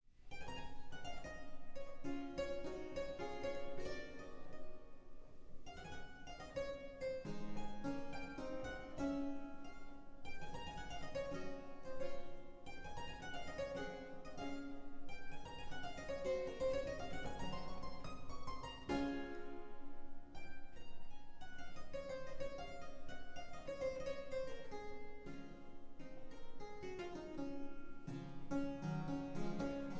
Clavichord